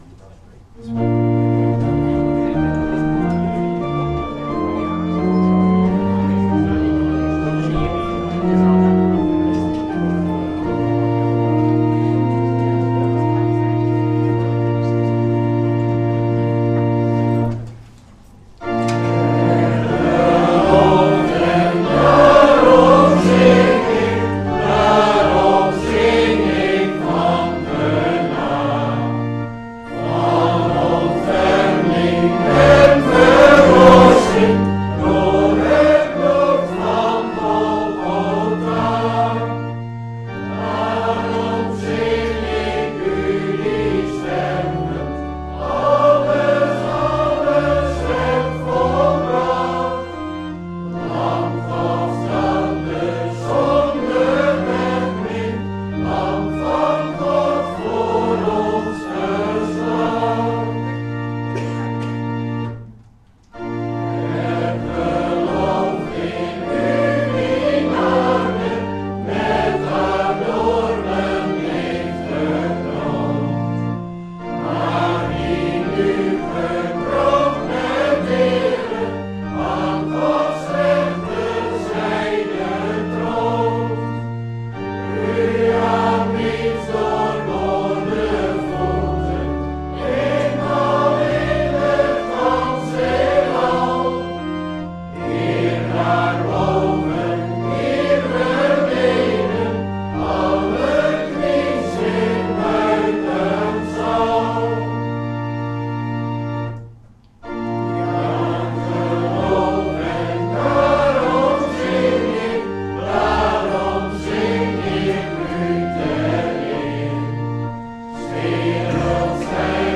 (Goede Vrijdag)
Vereiste velden zijn gemarkeerd met * Reactie * Naam * E-mail * Site ← Newer Preek Older Preek →